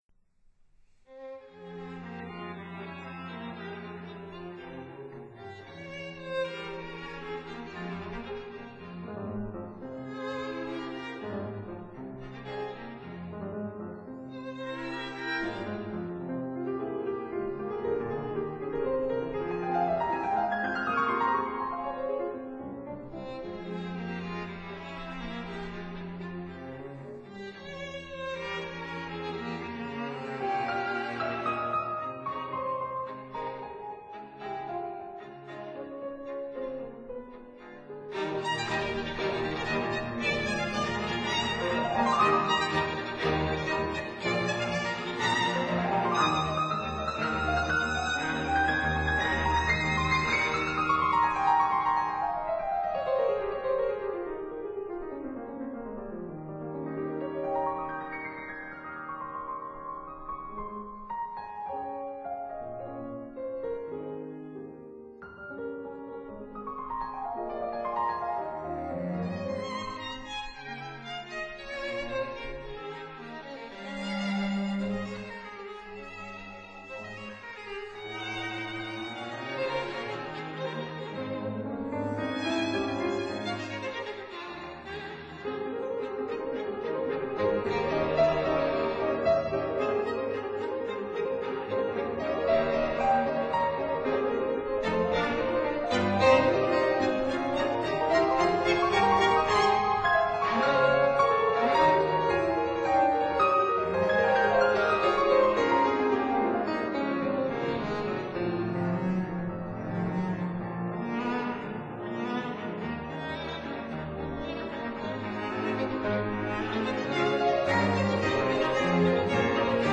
violin
viola
cello
piano